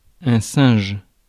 Ääntäminen
Tuntematon aksentti: IPA : /ˈpraɪmeɪt/